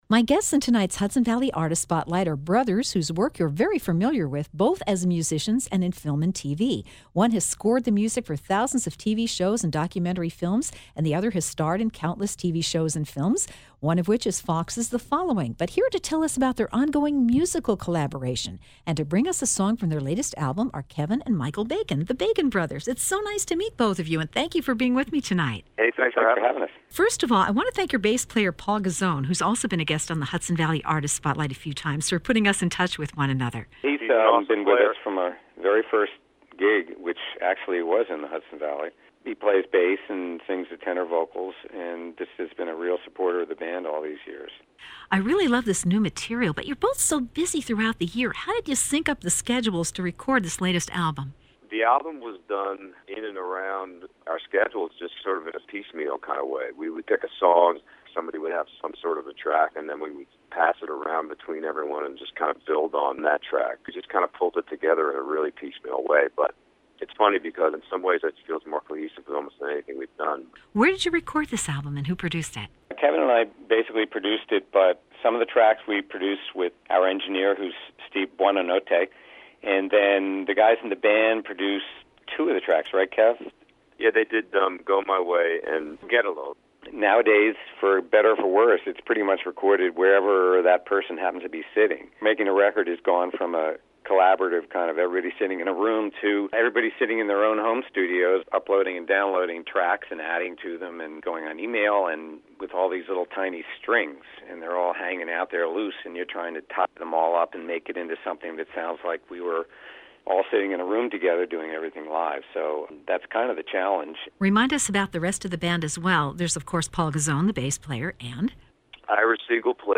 Bacon Brothers interview 7-17-15